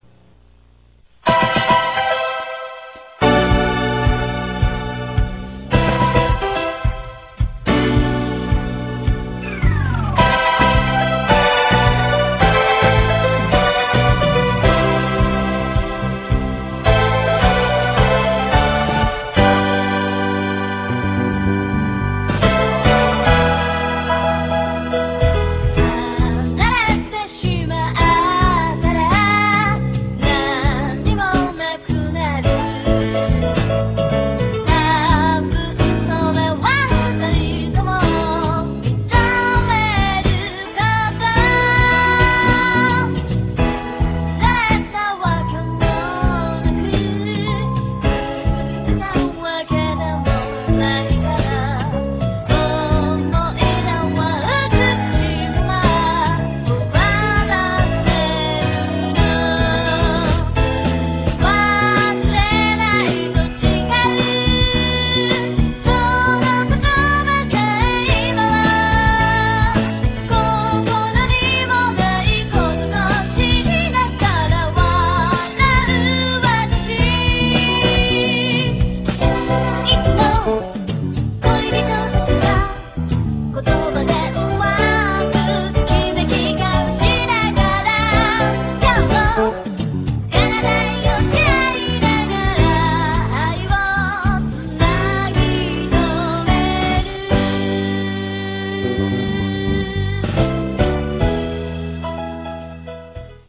渋谷のセンター街前からスクランブル交差点渡りながら採取した音は